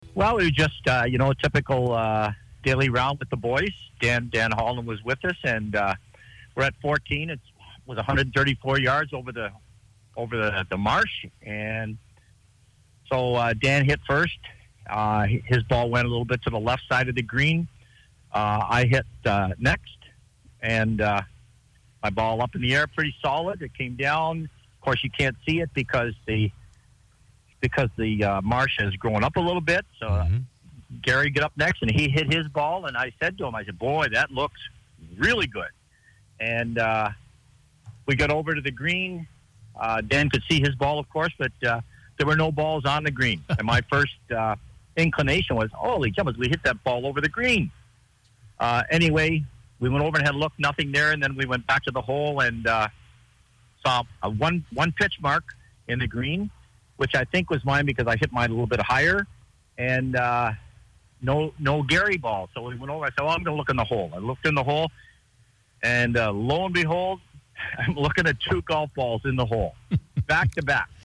MIX 97